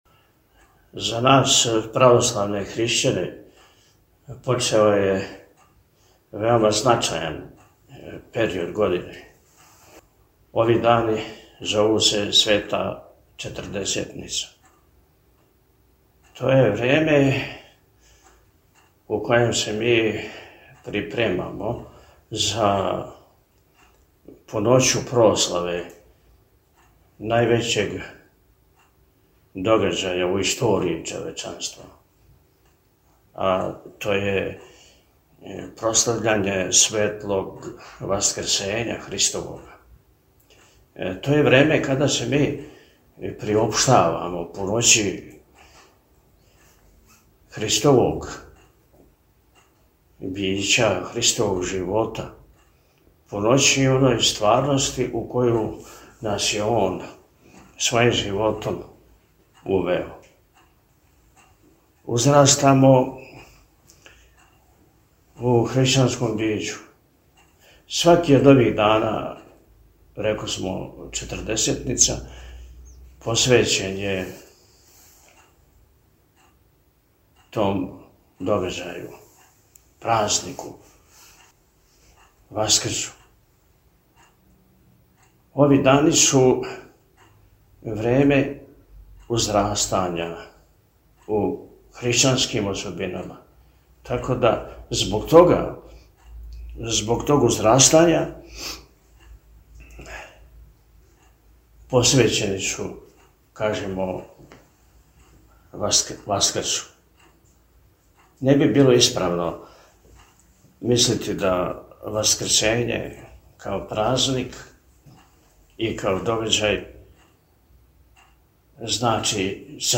У понедељак 3. марта 2025. године Његово Високопреосвештенство Архиепископ и Митрополит милешевски г. Атанасије упутио је, из Епархијског двора у Пријепољу, поруку свим верницима Епархије милешевске на почетку Васкршњег поста.